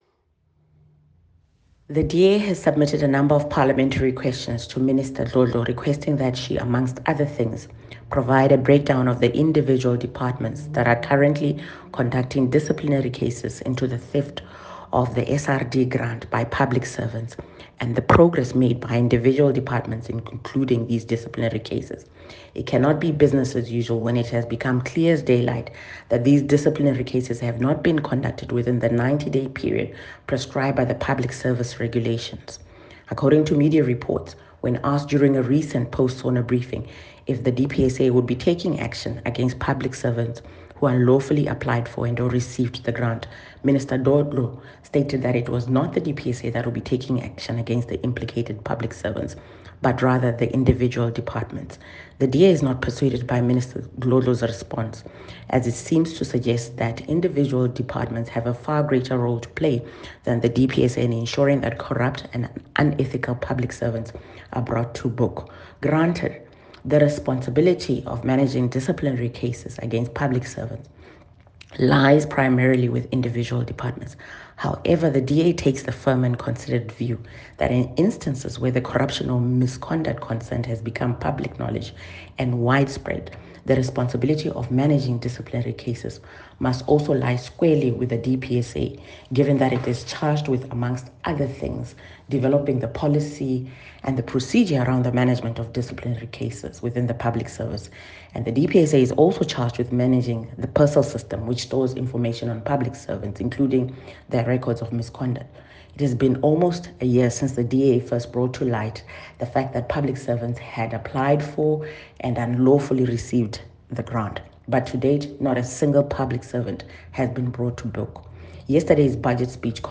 soundbite by Dr Mimmy Gondwe MP.